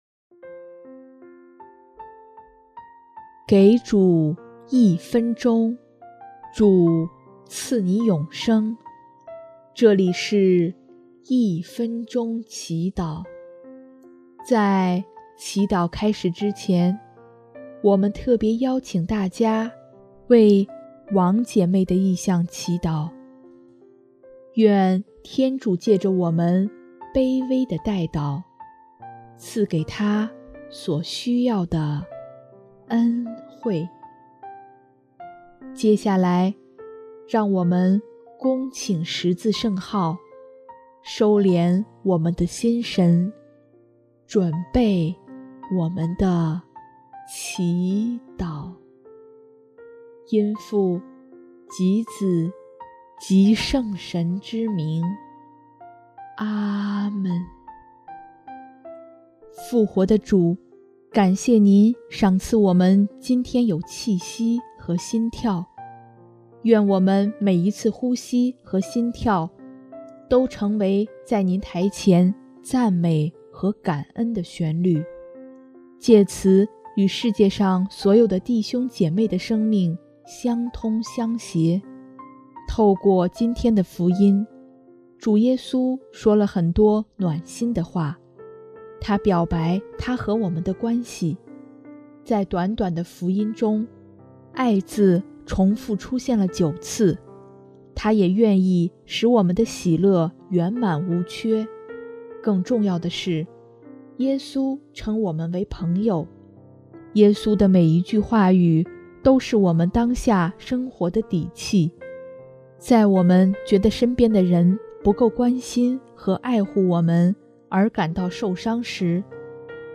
音乐：第二届华语圣歌大赛参赛歌曲《一生寻求你》